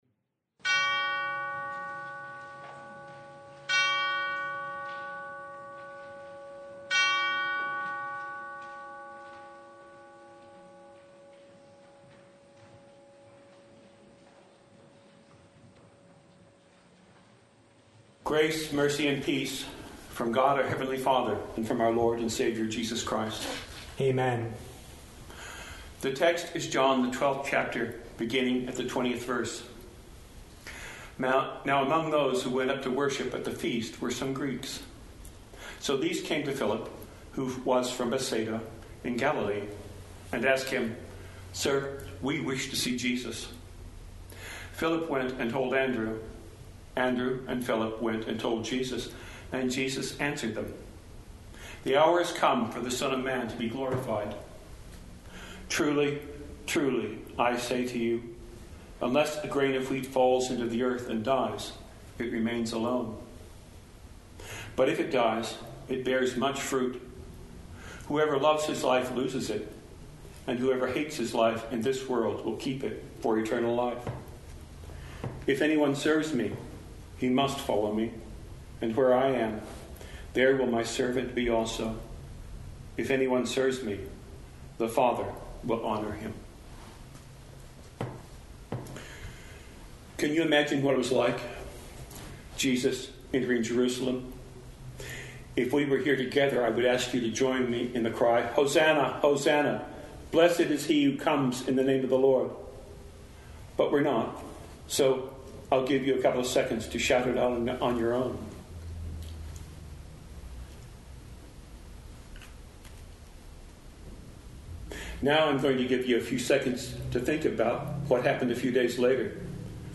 Sermon Only